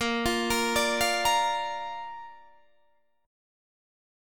Bb Chord
Listen to Bb strummed